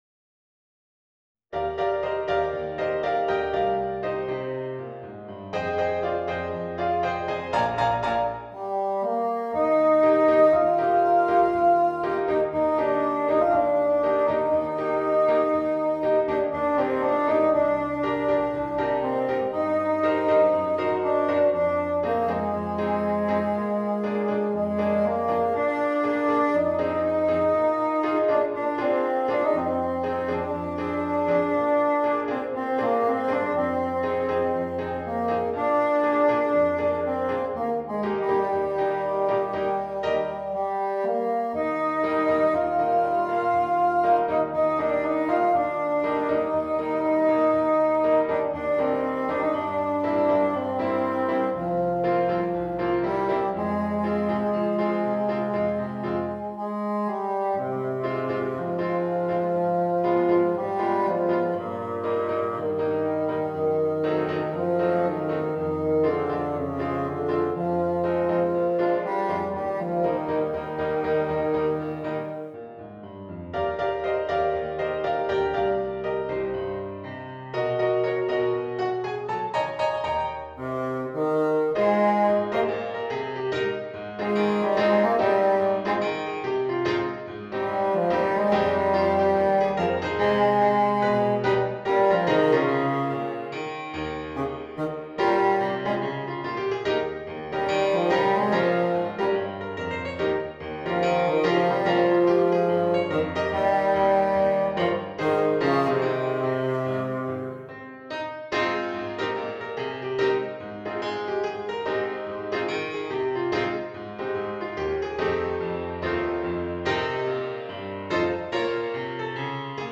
バスーン+ピアノ